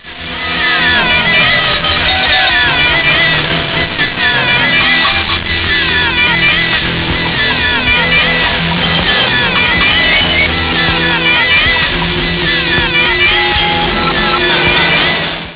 Using nothing but turntables